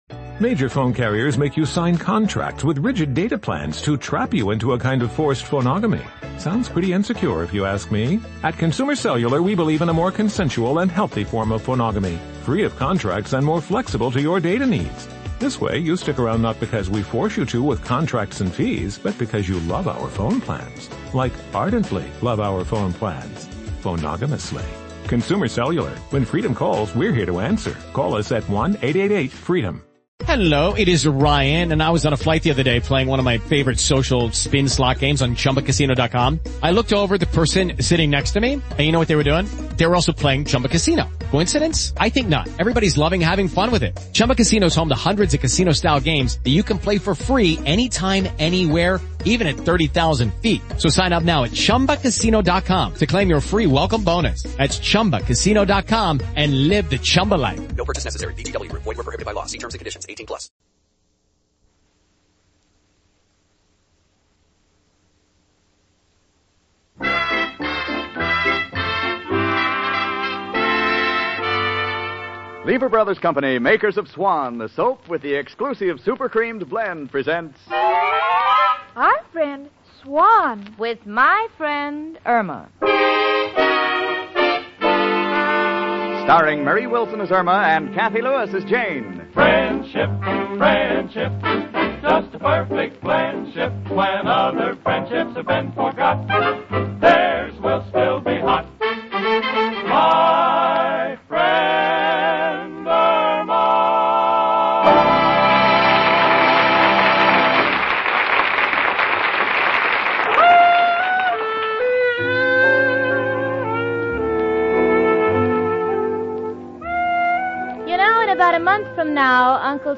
"My Friend Irma," the classic radio sitcom that had audiences cackling from 1946 to 1952! It was a delightful gem of a show, chronicling the misadventures of Irma Peterson, a ditzy yet endearing blonde, and her level-headed roommate Jane Stacy. Irma, played to perfection by the inimitable Marie Wilson, was the quintessential "dumb blonde."
Jane, portrayed by Cathy Lewis and later Joan Banks and Mary Shipp, served as the straight man, her exasperated reactions to Irma's antics only fueling the humor.The show's setting was a charmingly ramshackle boarding house in Manhattan, run by the ever-exasperated but good-natured Mrs. O'Reilly (Jane Morgan and Gloria Gordon).